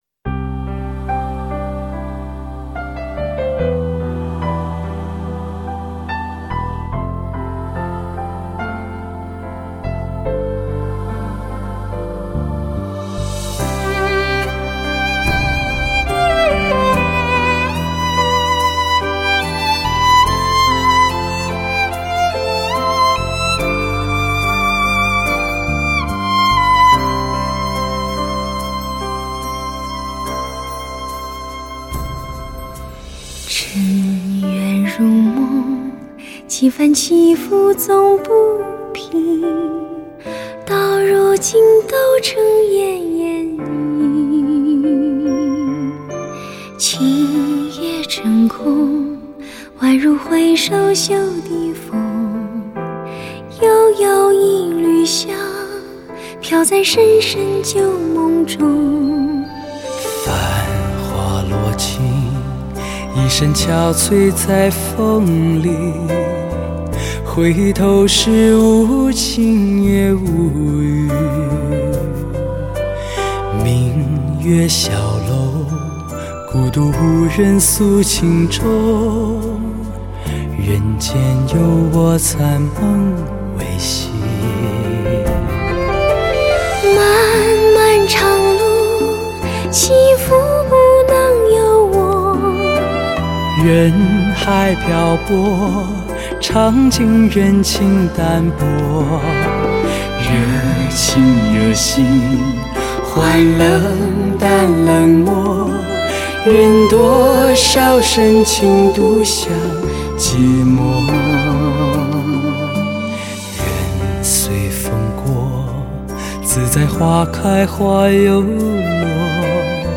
男女对唱经典：
震撼发烧兼具内涵完美录音
精彩选曲 男女自然天成的嗓音，绝佳的默契与演唱技巧，让你感怀万千...
悠扬动听的演唱，怎能不令人缠绵于往事，那些花儿，那些人，那些岁月...